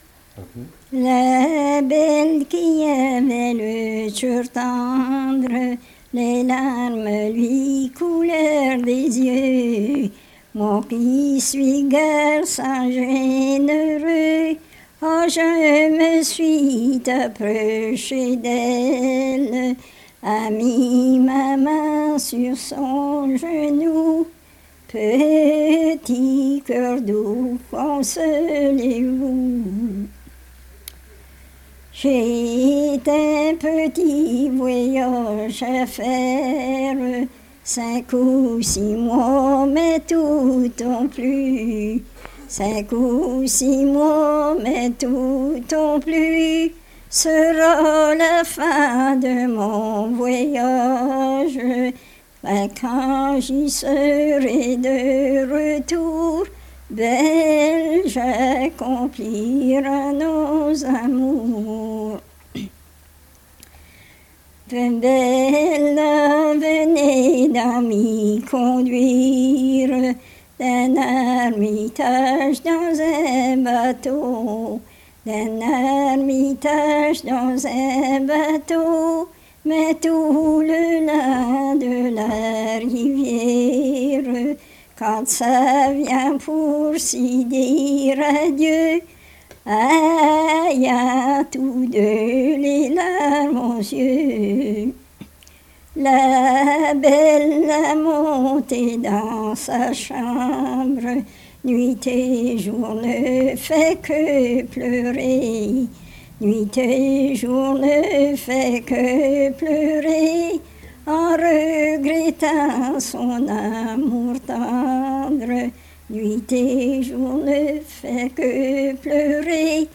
Chanson Item Type Metadata
Emplacement Cold Brook